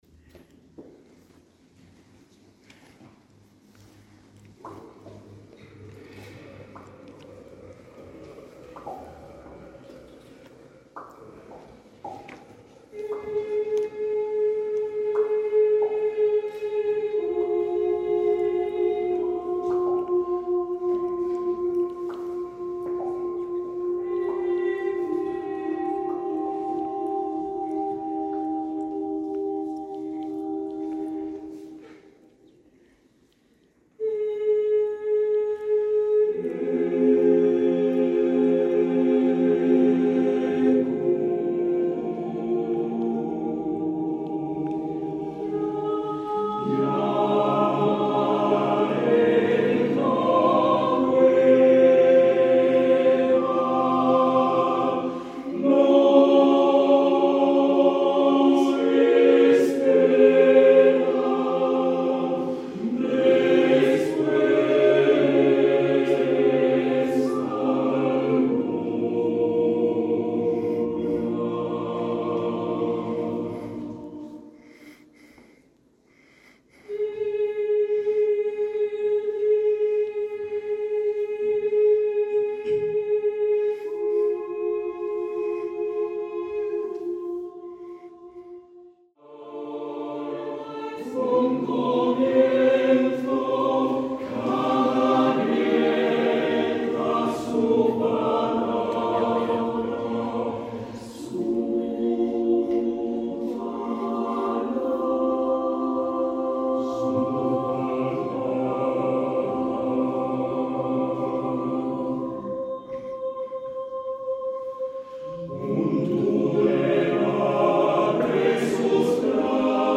• Plantilla: SATB divisi